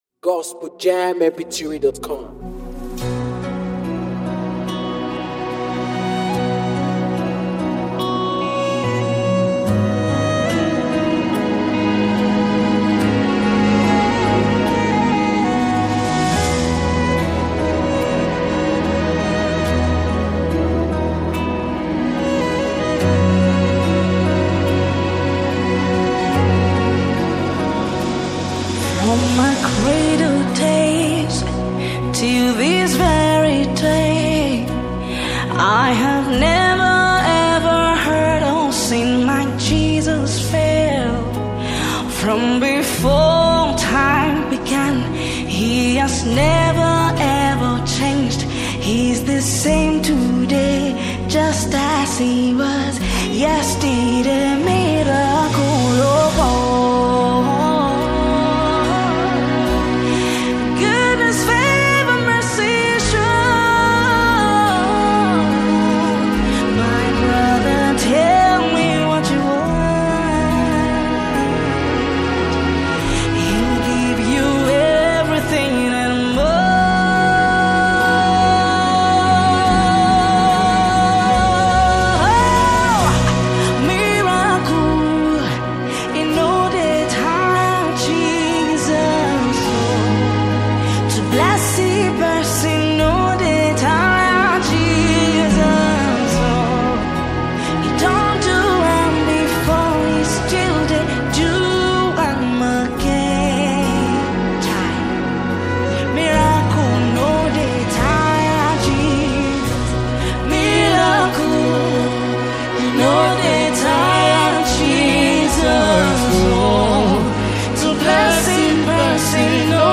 worship anthem